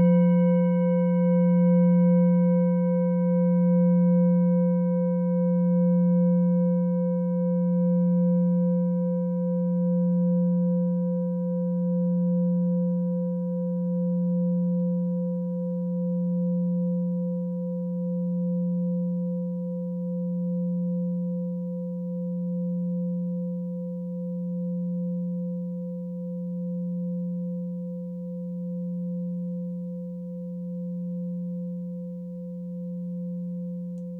Klangschalen-Typ: Bengalen
Klangschale Nr.1
Gewicht = 1550g
Durchmesser = 21,6cm
(Aufgenommen mit dem Filzklöppel/Gummischlegel)
klangschale-set-1-1.wav